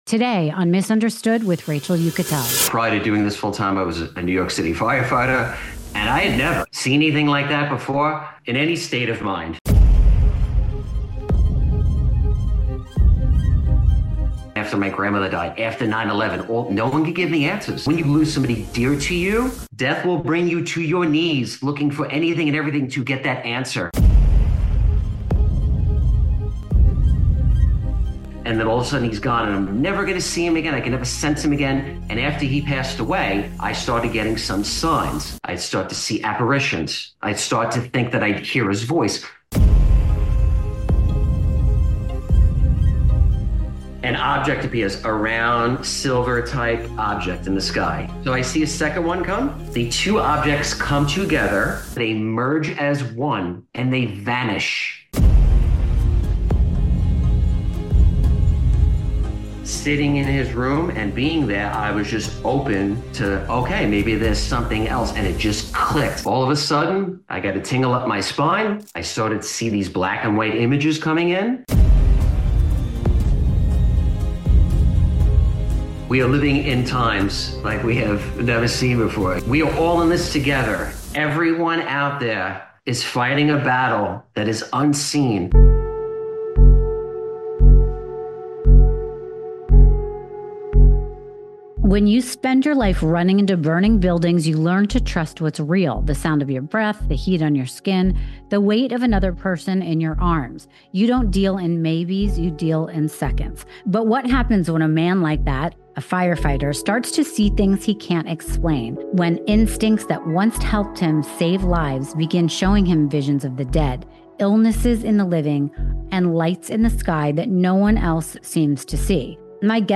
If you’ve ever wondered whether gifts like these are miracles, madness, or something in between — this conversation will make you question everything you thought you knew.